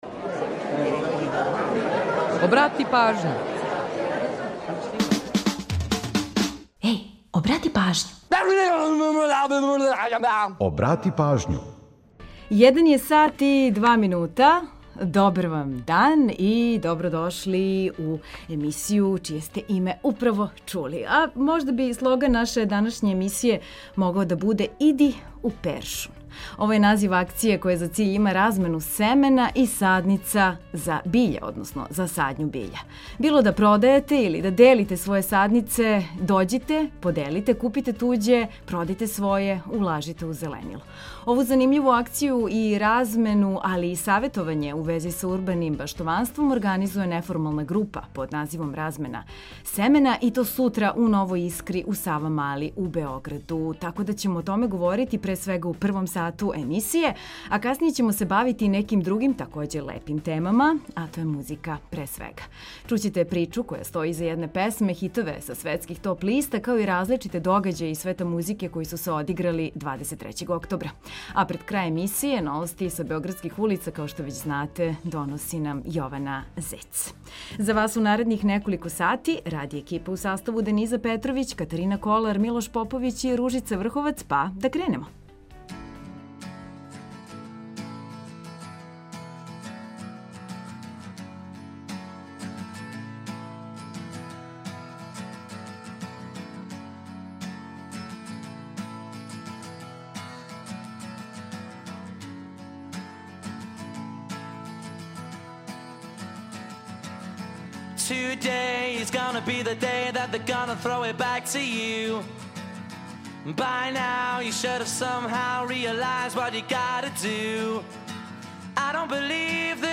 Чућете причу које стоји иза једне песме, хитове са светских топ листа као и различите догађаје из света музике који су се одиграли 23. октобра.